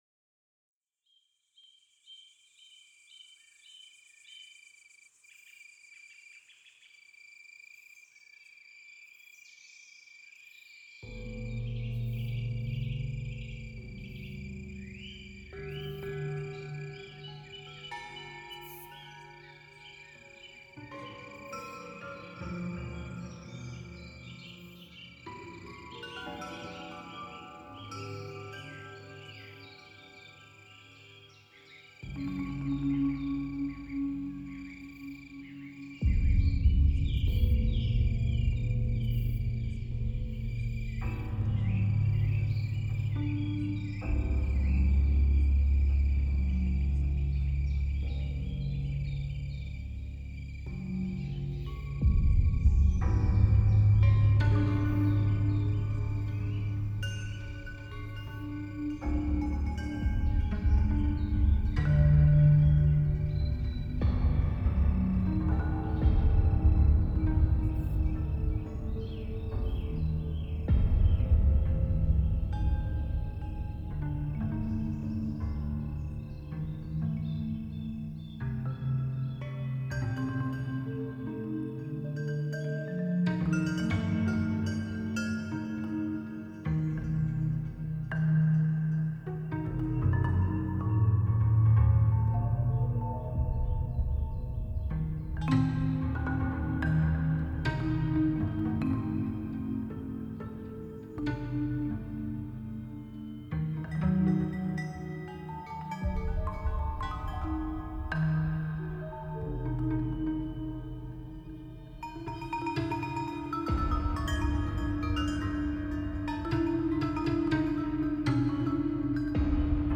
Déméter : Composition contemporaine pour son périple jusqu'aux enfers à la recherche de sa fille Perséphone enlevée par Hadès des Enfers.